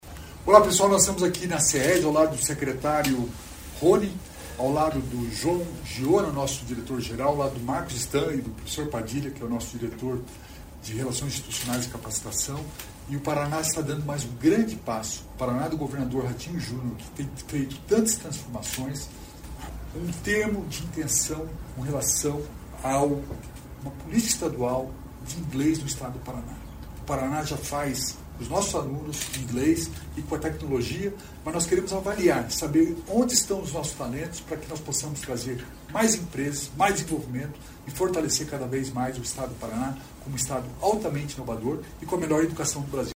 Sonora do secretário Estadual da Inovação e Inteligência Artificial, Alex Canziani, sobre o Paraná Global